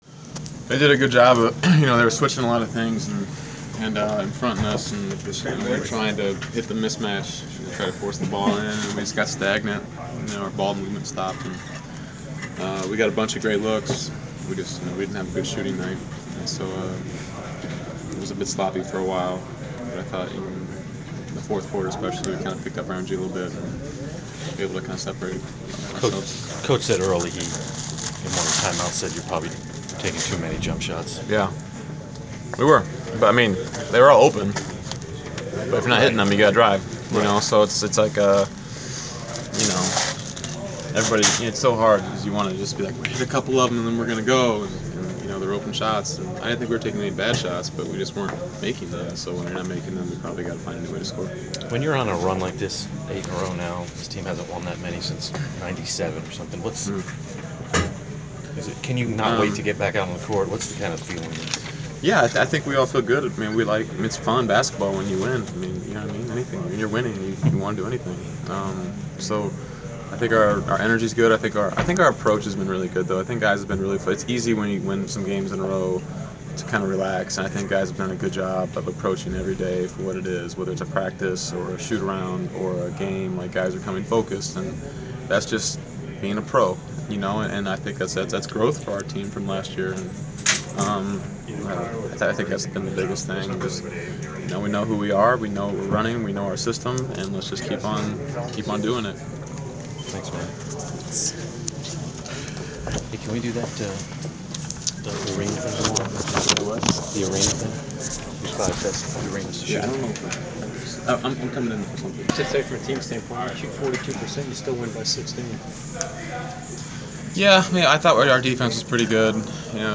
Inside the Inquirer: Postgame interview with Atlanta Hawks’ Kyle Korver (12/10/14)
We caught up with Atlanta Hawks’ guard Kyle Korver following the team’s 95-79 home win over the Philadelphia 76ers on Dec. 10. Topics included overall thoughts on the game, offensive effort and continued improvements during winning streak.